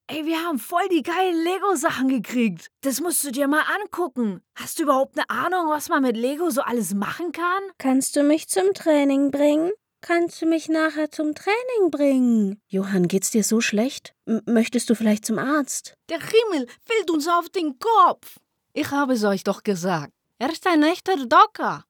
Im eigenen Studio nimmt sie dank Sennheiser MKH416 und Neumann TLM103 sauberes Audio in kurzer Zeit auf.
Fesselnde Stimme für eLearning und Erklärvideos. Freundliche Sprecherin für Imagevideos und Werbung. Stimme für Spielecharaktere
Sprechprobe: Sonstiges (Muttersprache):
Multi-faceted characters for games